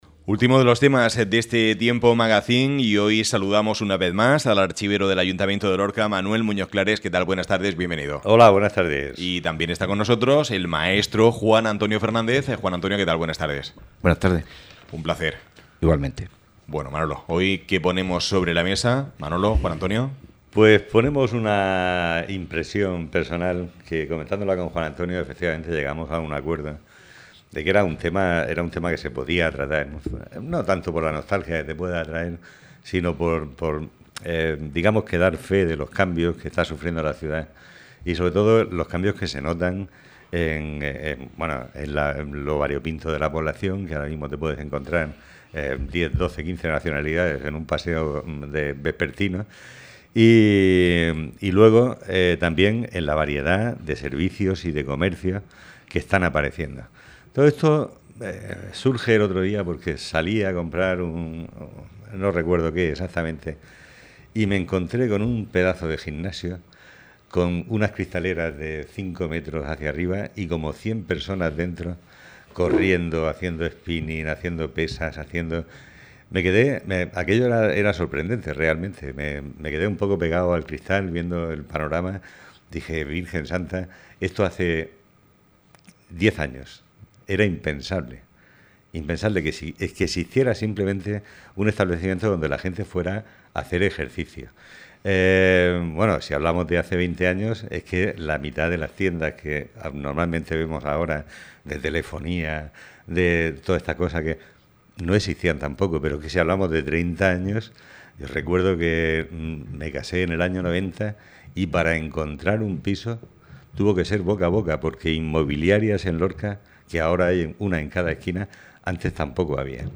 El comercio y los establecimientos de antes y los actuales, han sido el tema que exponían en el programa magazine de Área Lorca Radio